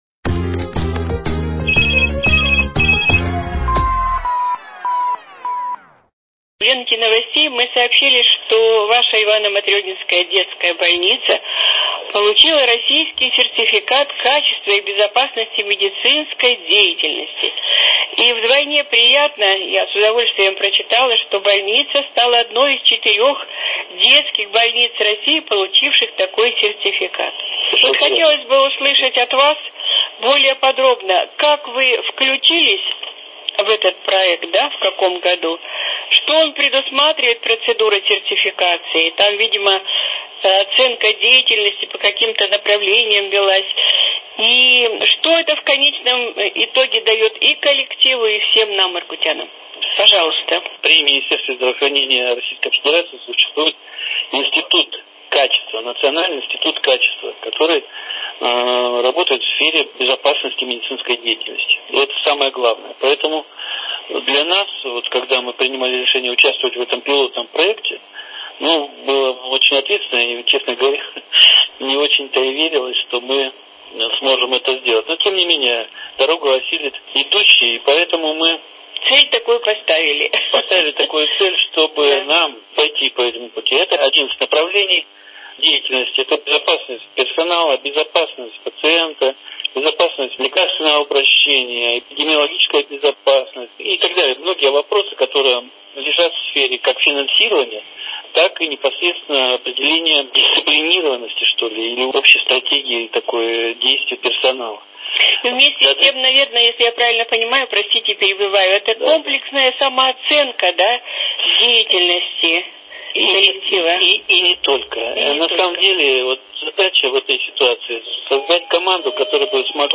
«Интервью по телефону» - Ивано-Матренинская детская больница получила российский сертификат качества.